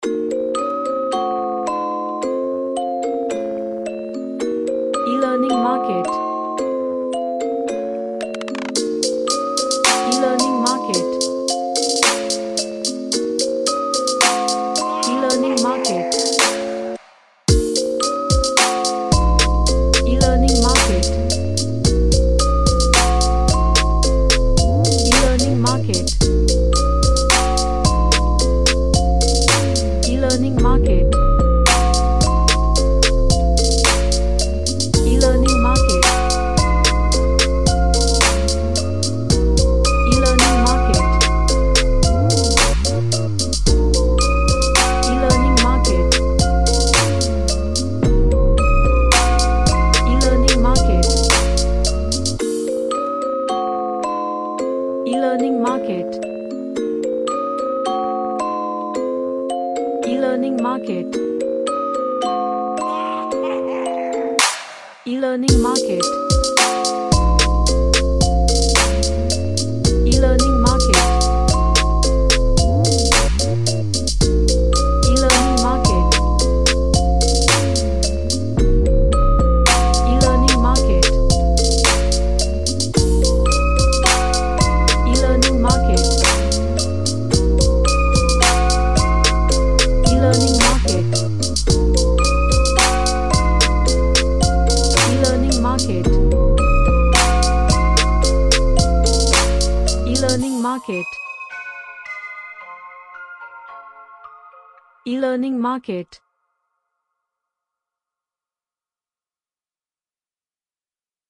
A dark sounding music box
Sad / Nostalgic